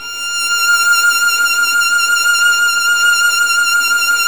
Index of /90_sSampleCDs/Roland L-CD702/VOL-1/STR_Violin 2&3vb/STR_Vln2 mf vb
STR  VL F 7.wav